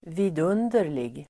Ladda ner uttalet
Uttal: [²v'i:dun:der_lig el. vid'un:der_lig]